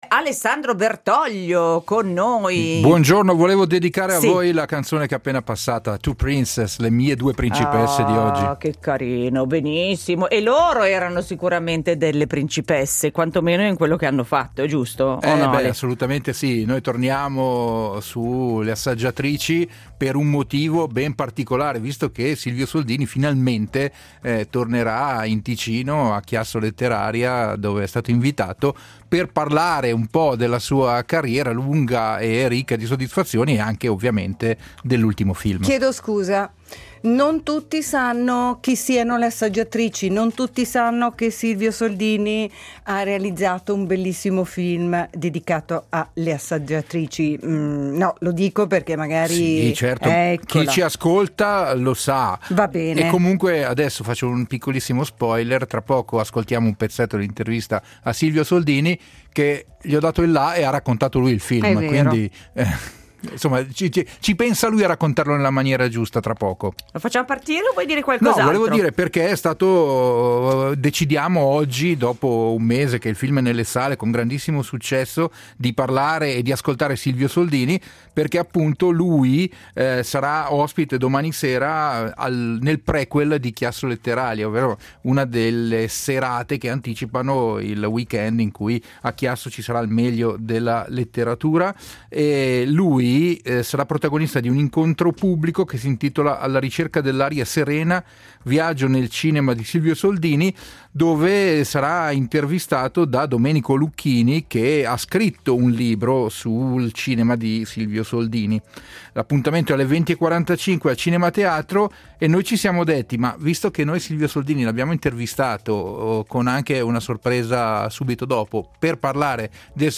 Renato Berta (direttore della fotografia) e Silvio Soldini (regista)